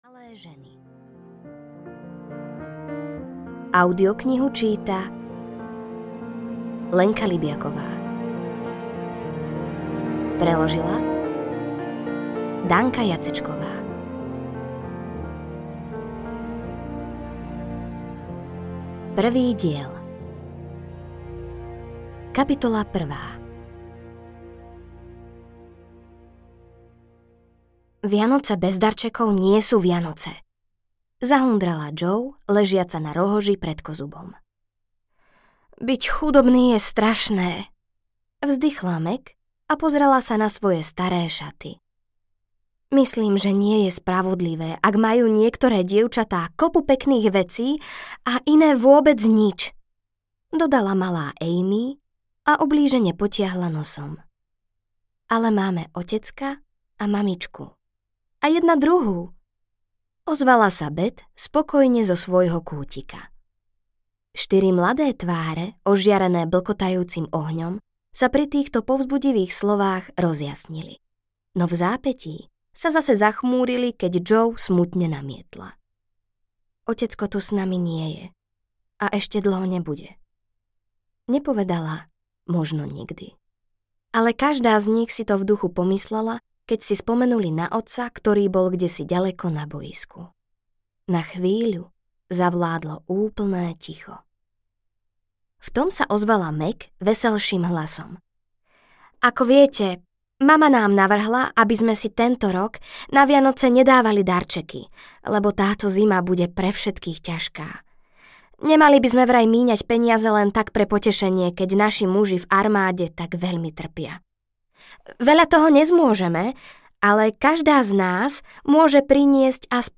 Malé ženy audiokniha
Ukázka z knihy